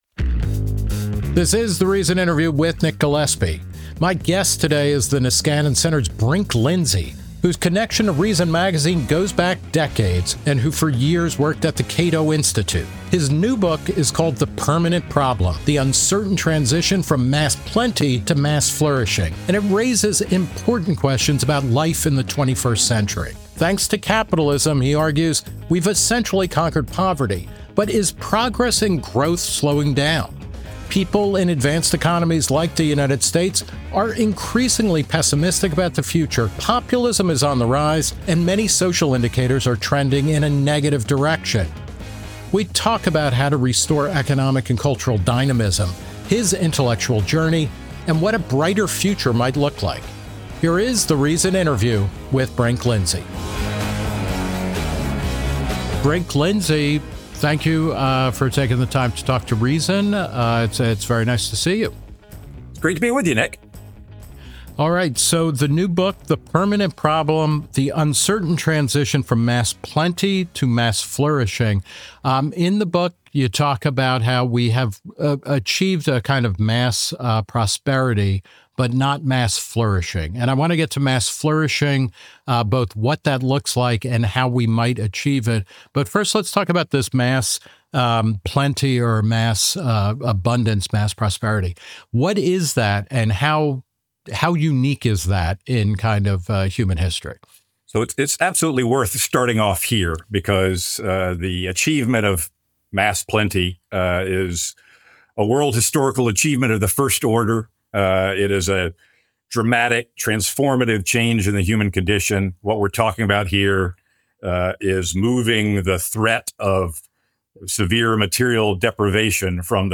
Nick Gillespie talks with him about how to restore economic and cultural dynamism, his intellectual journey, and what a brighter future might look like.